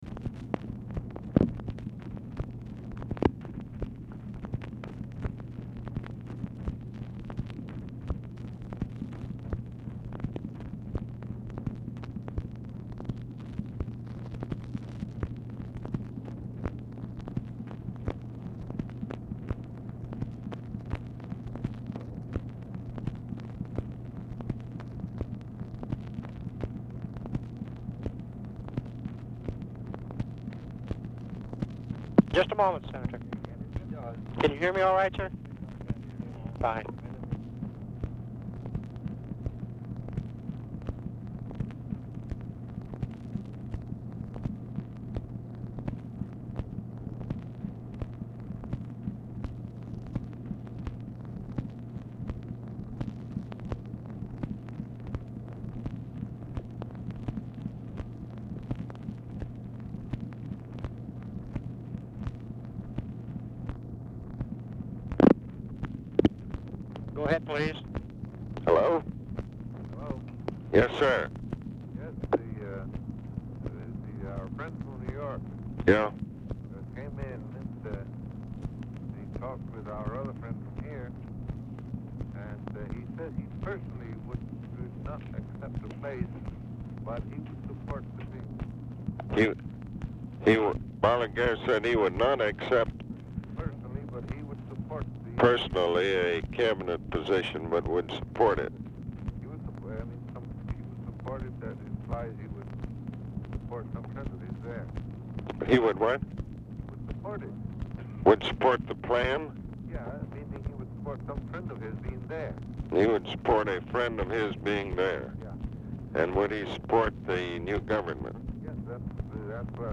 Telephone conversation # 7741, sound recording, ABE FORTAS and LUIS MUNOZ-MARIN, 5/18/1965, 3:36AM | Discover LBJ
Format Dictation belt
Specific Item Type Telephone conversation